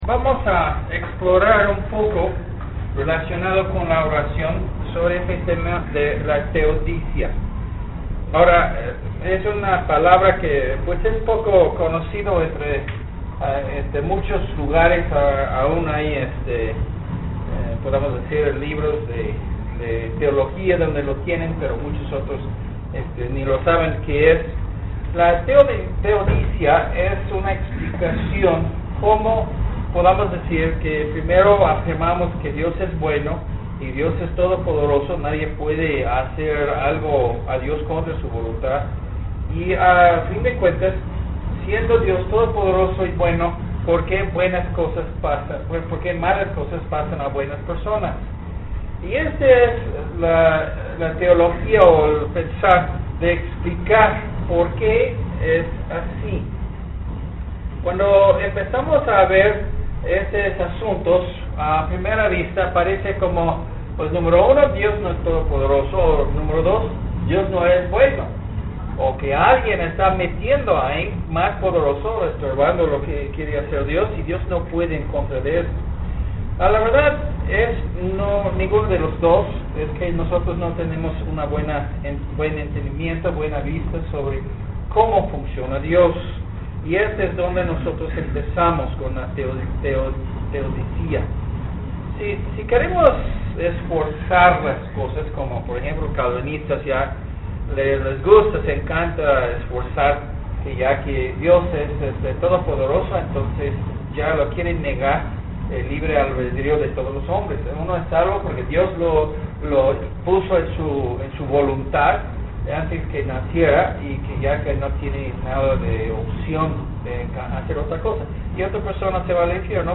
doct10 La Teodicia Sermón en Audio